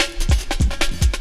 16LOOP01SD-L.wav